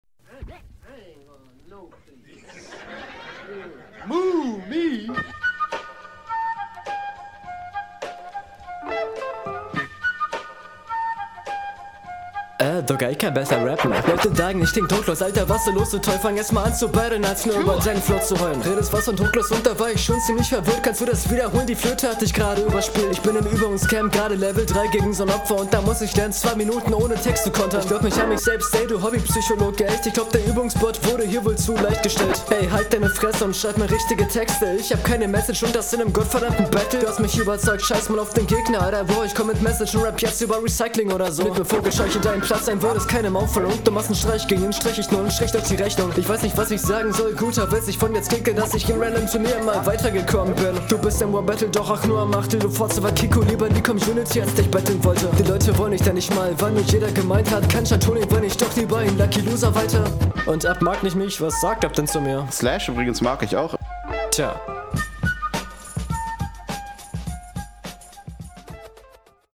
Flow:auch sehr sauber und angenehm, wobei ich deinen Gegner noch ein kleinen ticken vorne sehe! …